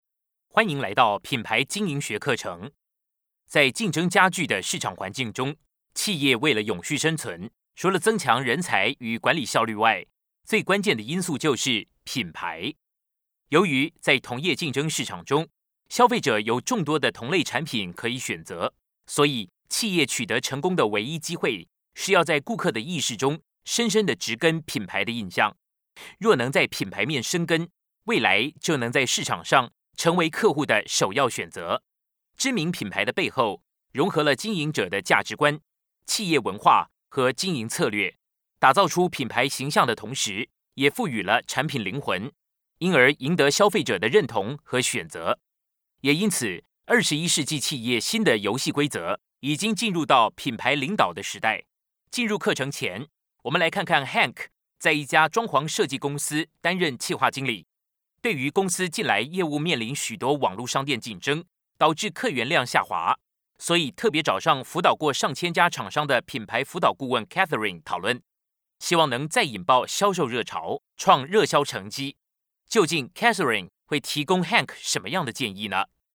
Traditional (Tayvan) Seslendirme
Erkek Ses